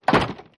Door Slams, Small, Wooden Door.